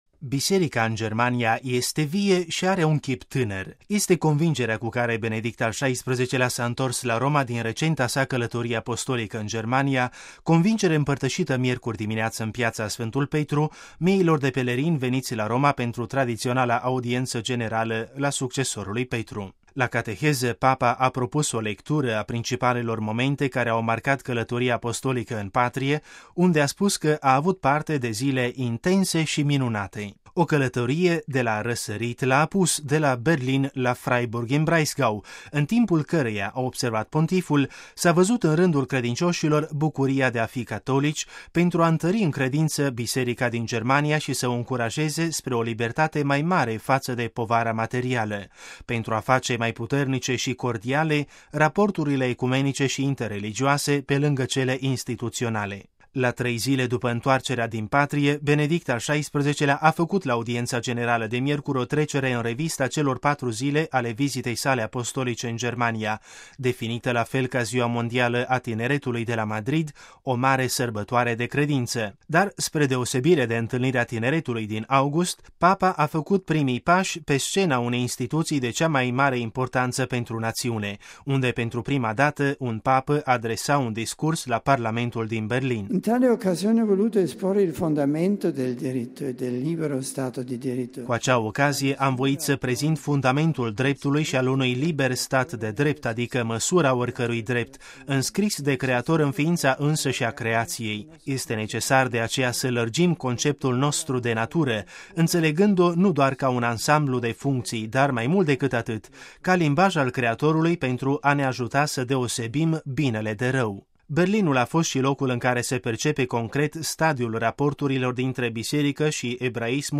Primiţi acum şi Binecuvântarea Apostolică, invocată de Benedict al XVI la audienţa generală de miercuri în Piaţa Sfântul Petru, binecuvântare ce ajunge acum la toţi ascultătorii care o primesc în spirit de credinţă.